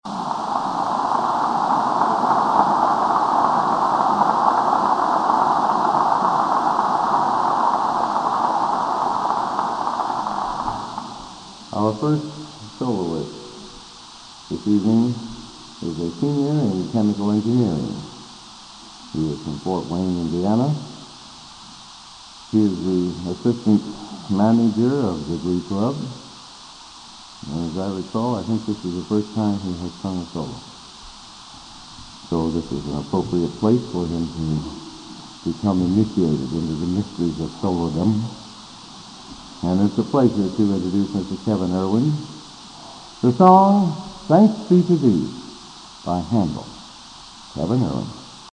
Collection: Broadway Methodist, 1982
Genre: | Type: Broadway Methodist